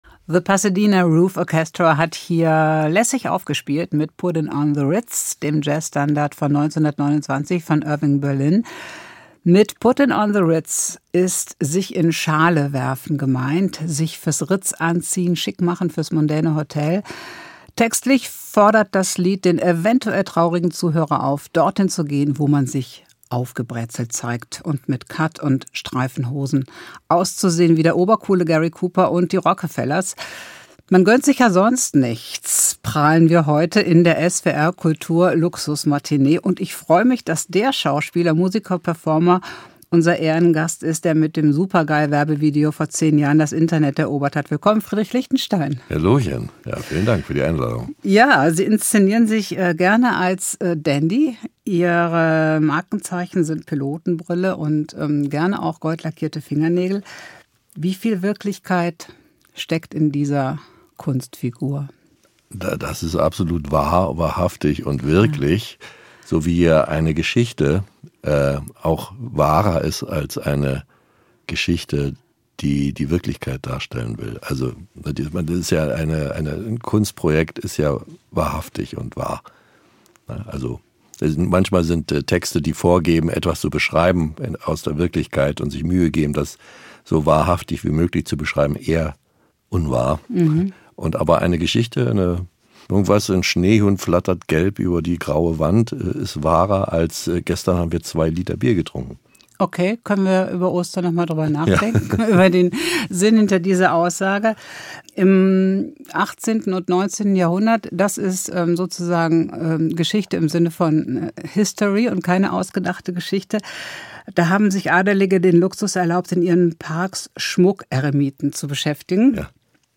Pilotenbrille, goldene Fingernägel, gepflegter Bart und eine Stimme zum Dahinschmelzen: Friedrich Liechtenstein liebt den Luxus und spielt mit Luxus-Klischees.